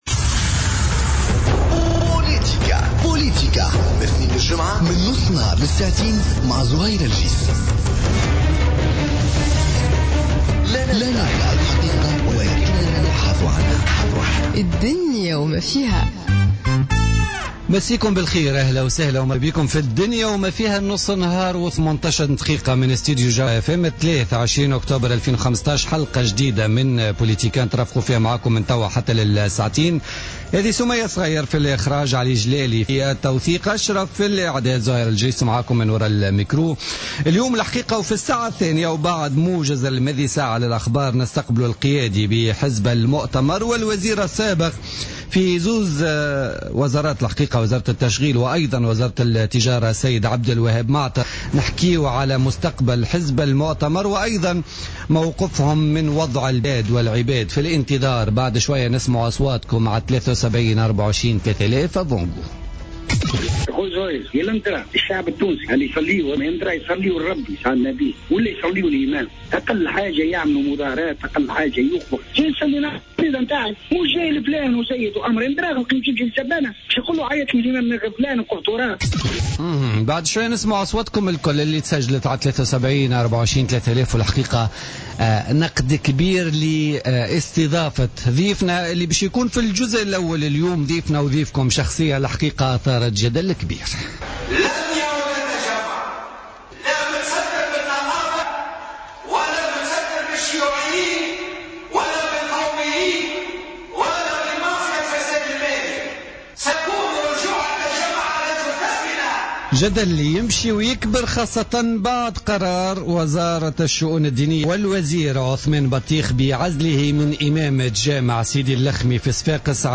Interview avec Abdelwaheb Maâter